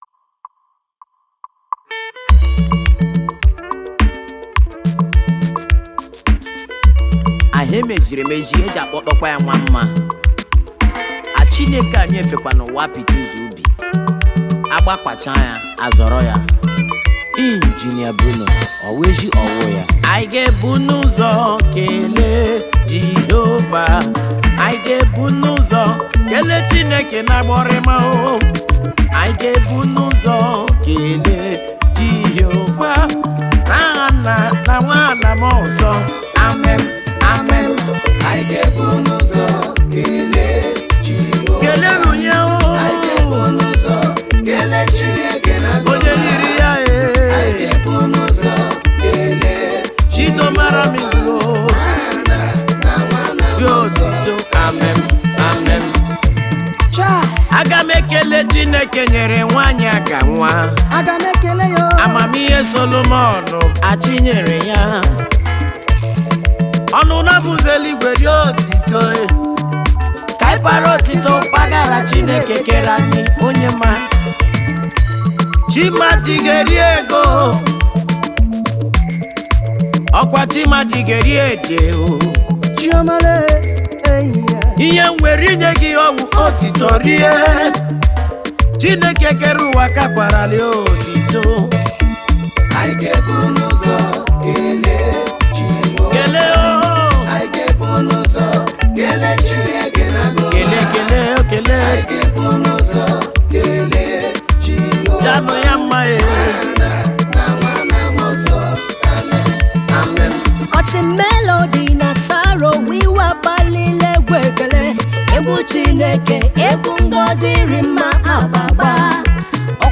highlife track
highlife tune